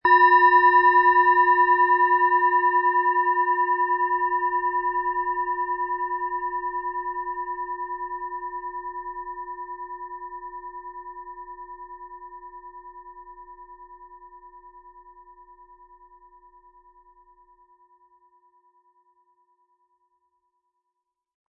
Planetenschale® Hindernisse meistern & Hindernisse überwinden mit Wasser-Ton, Ø 12,3 cm, 260-320 Gramm inkl. Klöppel
Diese tibetanische Wasser Planetenschale kommt aus einer kleinen und feinen Manufaktur in Indien.
SchalenformBihar
MaterialBronze